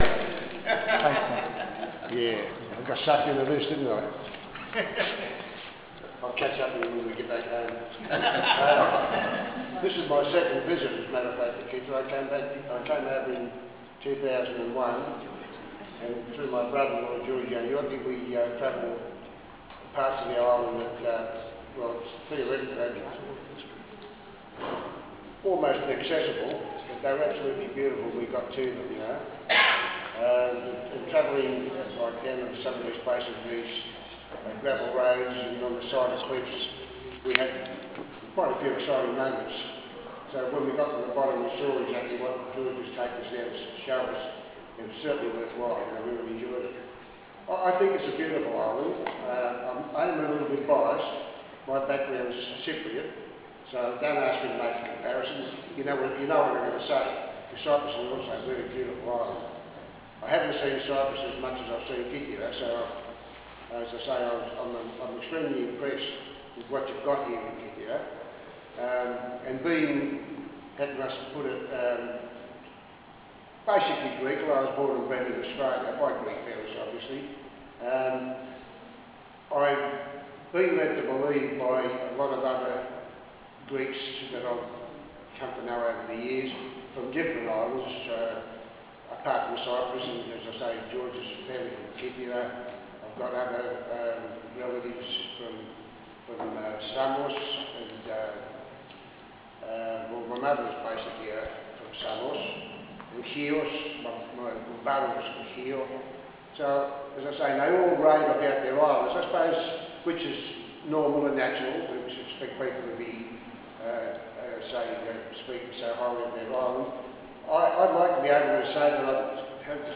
Here are some of the speeches from the Discover Your Roots Day on Kythera in August 2006.